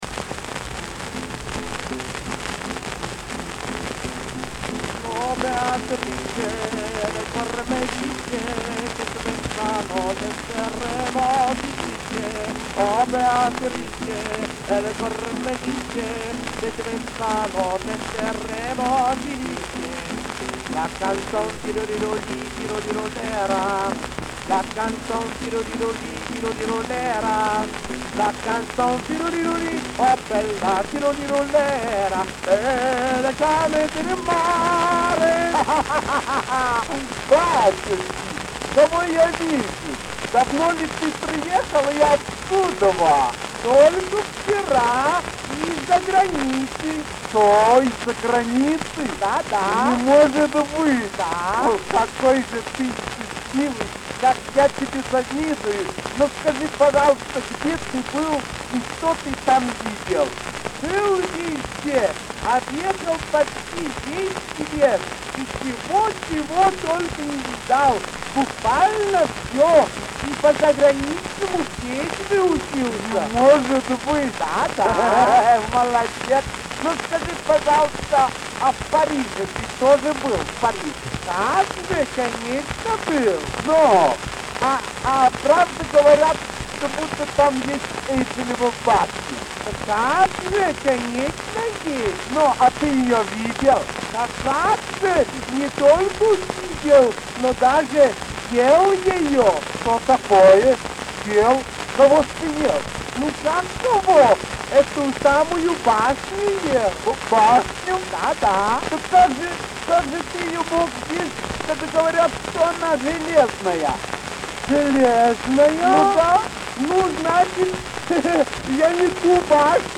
Вот ещё одна сценка... если кому-то интересно.